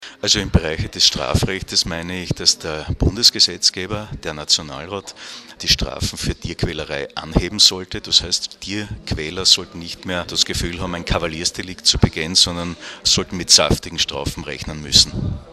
Graz (5. Juni 2013).- Gemeinsam mit dem zuständigen Landesrat Gerhard Kurzmann präsentierte die steirische Tierschutzombudsfrau Barbara Fiala-Köck heute Vormittag (05.06.2013) im Medienzentrum Steiermark ihren aktuellen Tätigkeitsbericht.